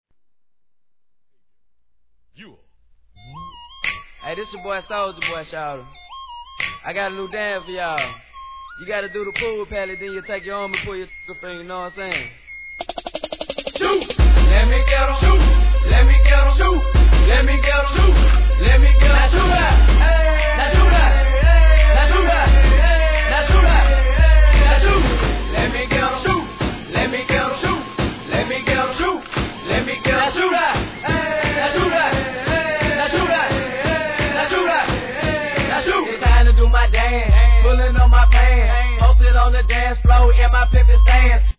HIP HOP/R&B
激しく打ち鳴らされた銃声で刻むビートがフロアーをシェイクさせること間違いなしっ！！